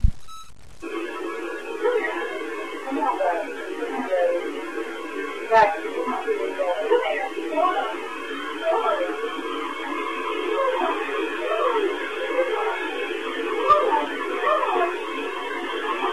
EVP6 Filtered
This EVP was recorded at a business in Buford, GA.
We have never heard an EVP that sounds like this, but like all the others, it was not coming from any of us.